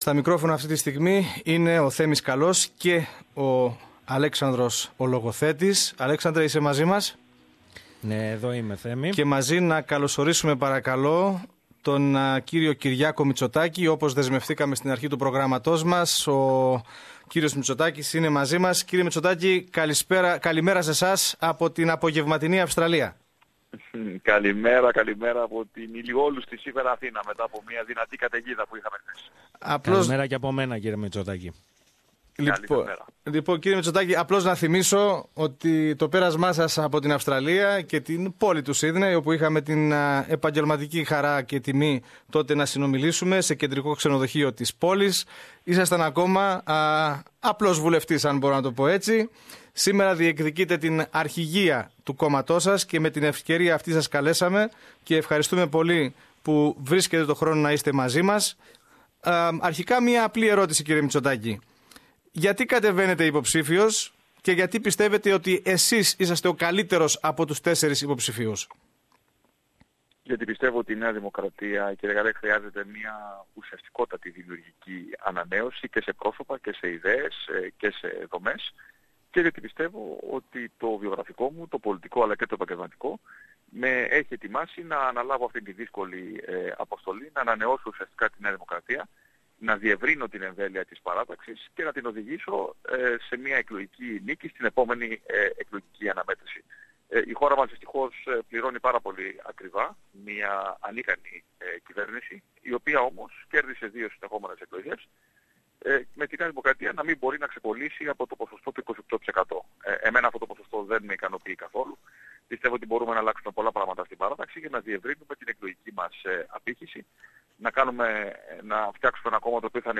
Ο υποψήφιος για την ηγεσία της Νέας Δημοκρατίας, Κυριάκος Μητσοτάκης, μίλησε στο πρόγραμμα μας ξετυλίγοντας το πολιτικό όραμα του για το κόμμα, ενώ αναφέρθηκε μεταξύ άλλων και στο δικαίωμα ψήφου των ομογενών της Αυστραλίας, στην εκλογή προέδρου.